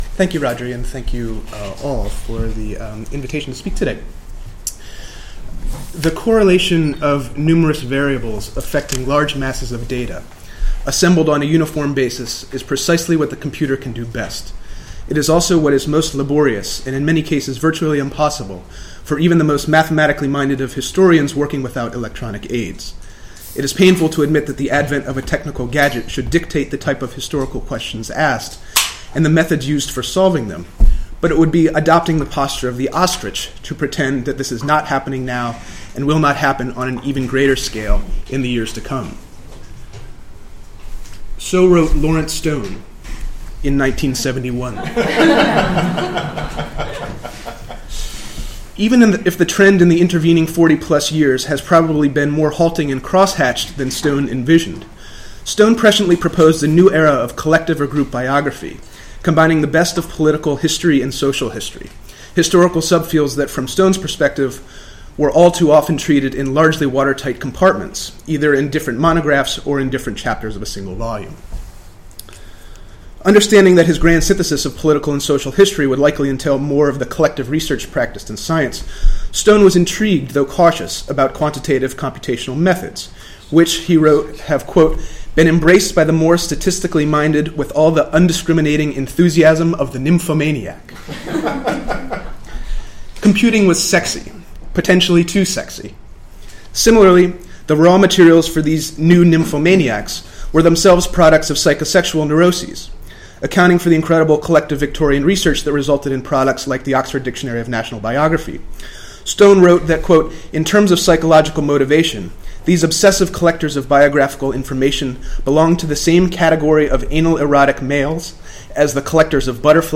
2013 Seminar Series